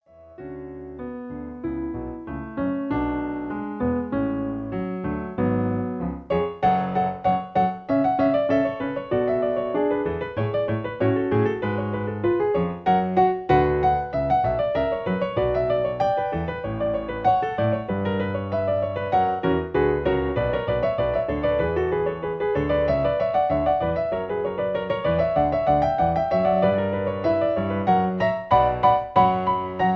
In F sharp. Piano Accompaniment